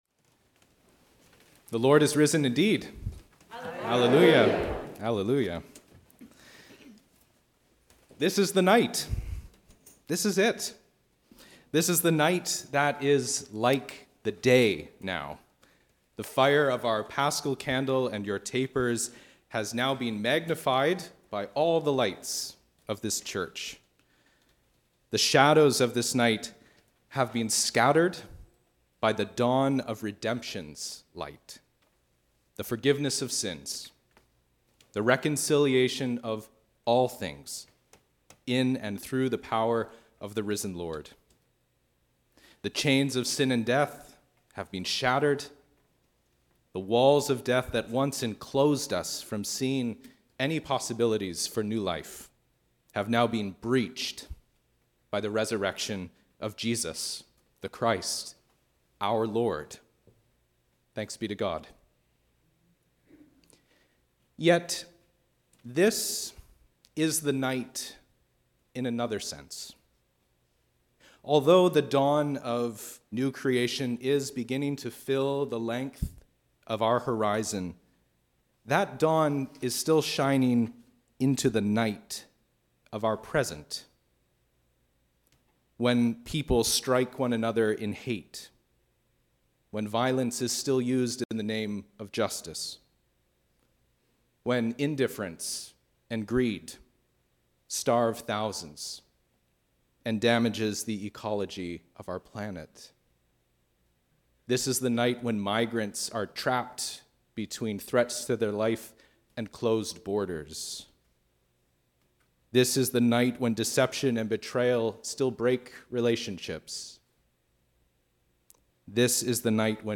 This is the night! A Sermon on the Great Vigil of Easter
Great-Vigil-of-Easter-Sermon.mp3